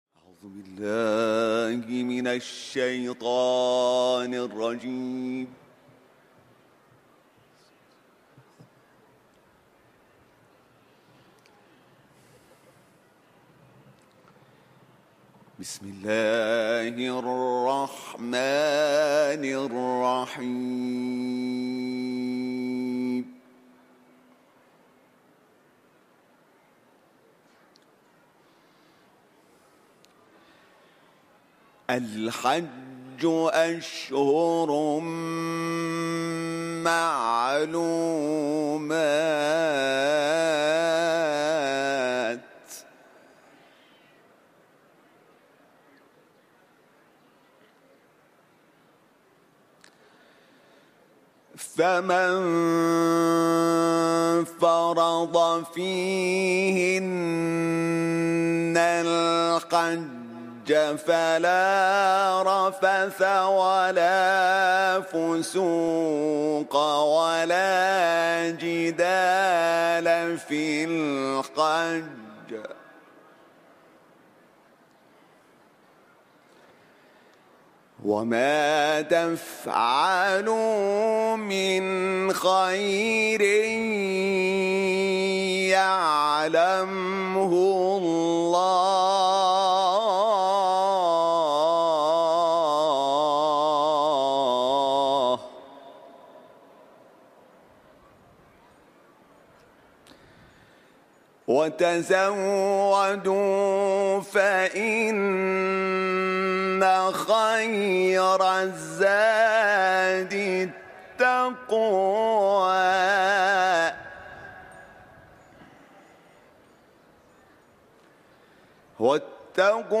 صوت تلاوت آیات ۱۹۷ تا ۱۹۹ از سوره «بقره» با صدای حمید شاکرنژاد، قاری بین‌المللی قرآن که در محفل قرآنی حرم رضوی اجرا شده است، تقدیم مخاطبان ایکنا می‌شود.
برچسب ها: حمید شاکرنژاد ، سوره بقره ، تلاوت قرآن